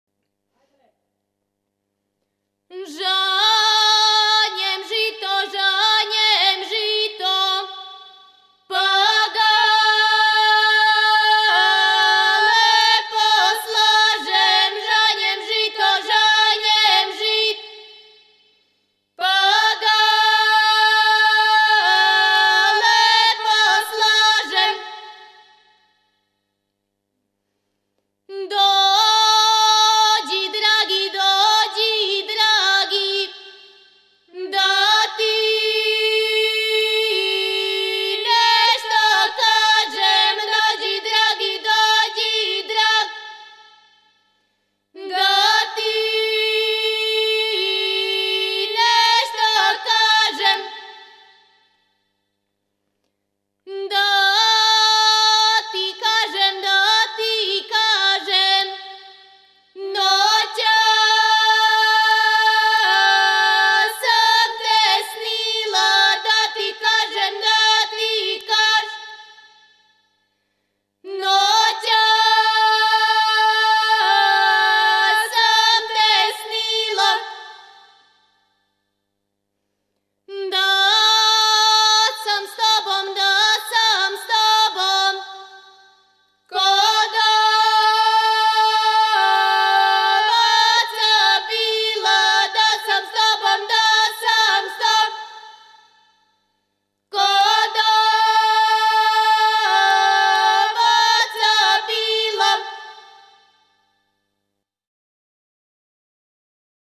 Порекло песме: Околина Ивањице Начин певања: На глас. Напомена: Жетелачка песма